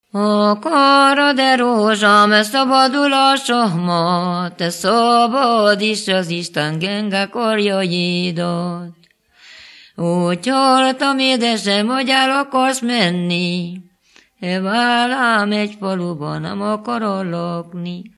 Moldva és Bukovina - Moldva - Klézse
Stílus: 7. Régies kisambitusú dallamok
Szótagszám: 6.6.6.6